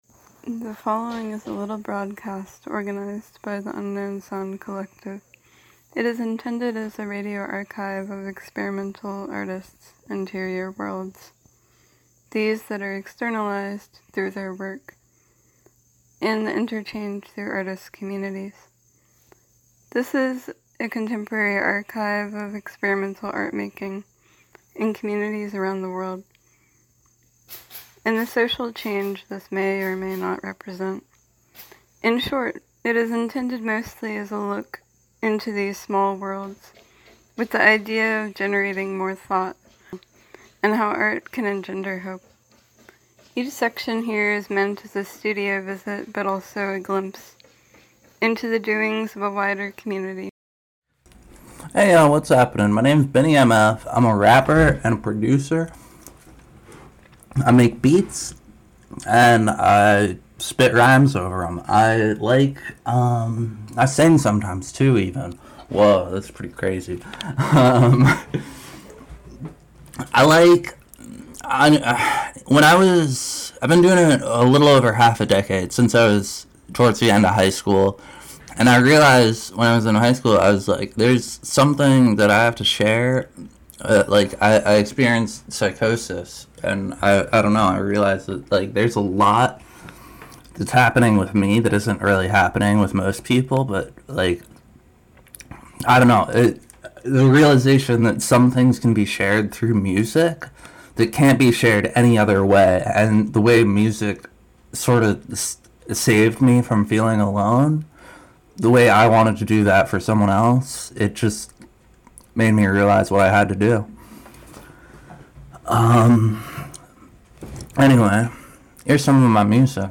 "unknown sounds" is intended as a radio archive of experimental artists’ interior worlds, these that are externalized through their work, and the interchange through artist communities.
Each section here is meant as a studio visit, but also a glimpse into the doings of a wider community and the cultural, political repercussions of experimental sound and art-making.